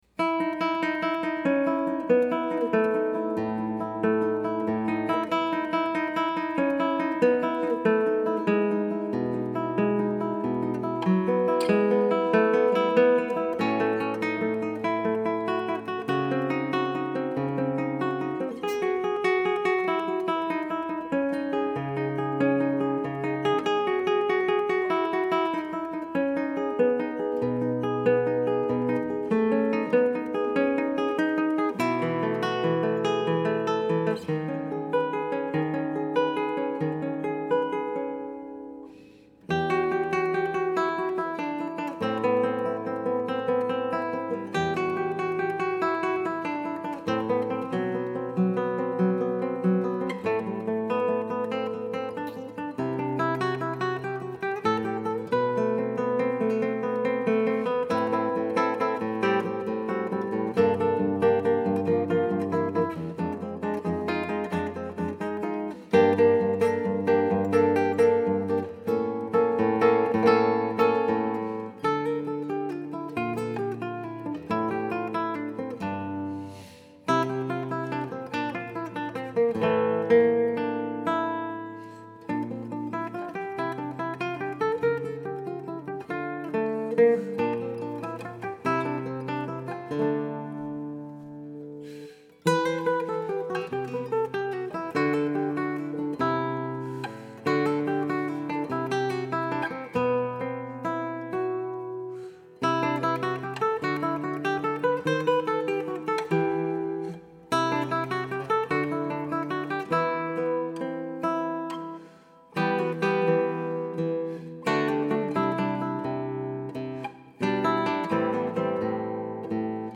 per chitarra